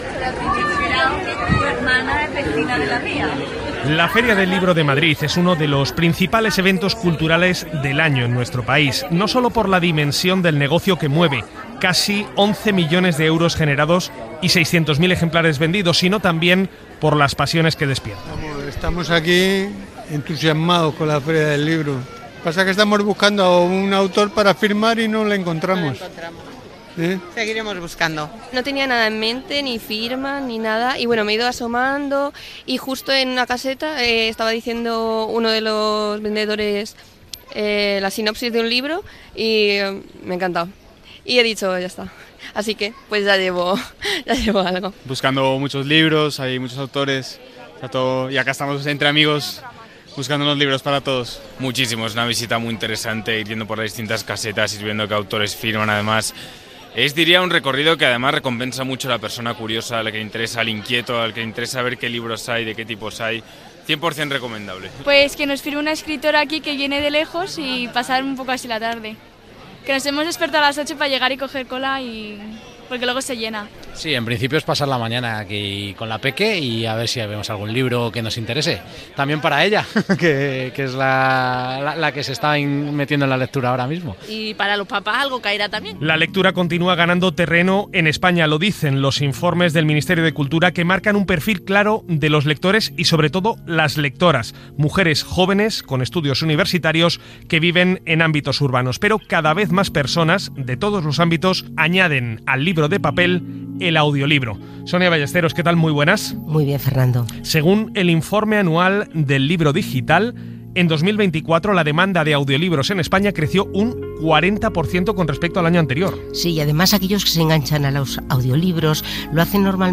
La Feria del Libro de Madrid, dades sobre els audiollibres i com s'enregistren, Entrevista